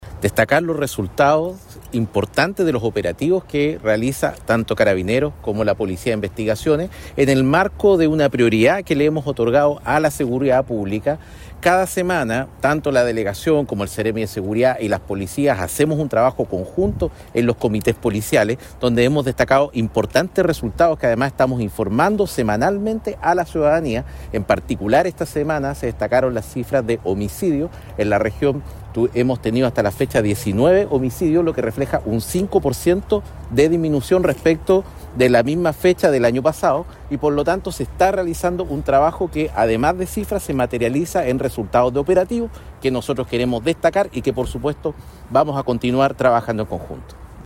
El delegado presidencial del Biobío, Julio Anativia, se refirió al impacto del procedimiento.